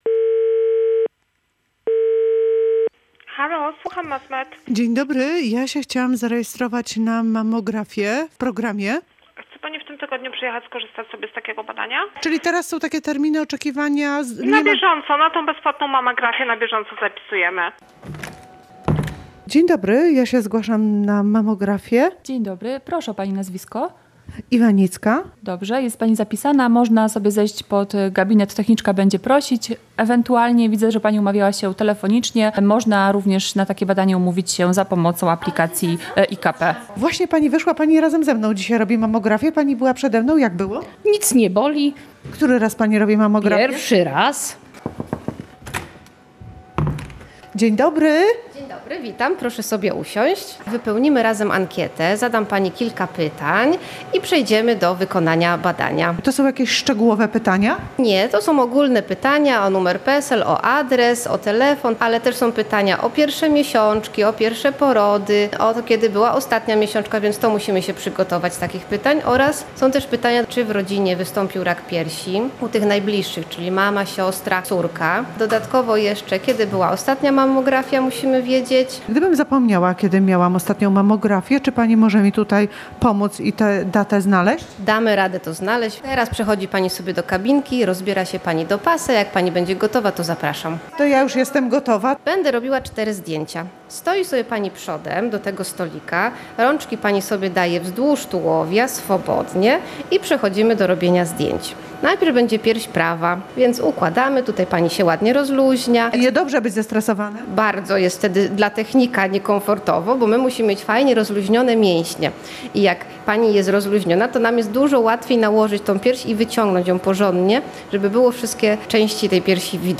Różowy październik bez kolejek. Nasza reporterka wybrała się na mammografię